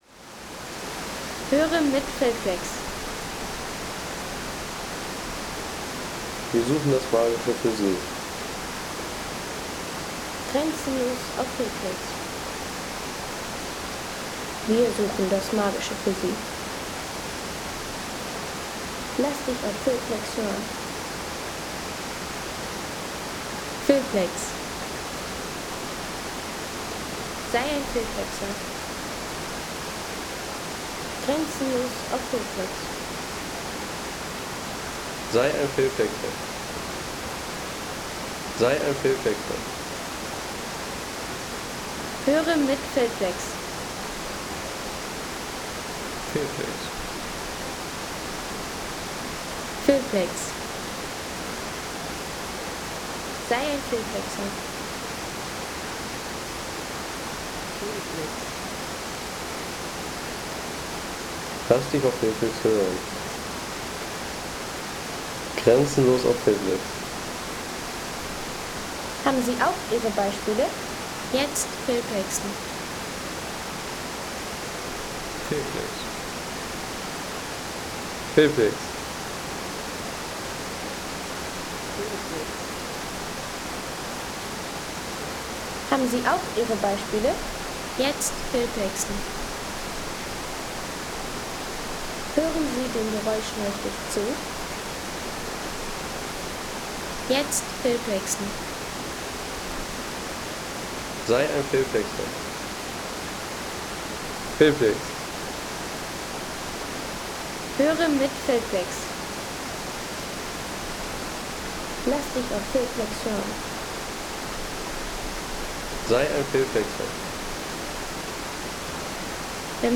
Waterfall Moment Gesäuse – Sound at Palfau Gorge
Gentle waterfall sounds at the first cascade of the Palfau Gorge in Gesäuse National Park – recorded from a wooden viewing platform.
Peaceful waterfall soundscape from Gesäuse National Park – recorded at the 22-meter cascade on the way to the famous Palfau Gorge.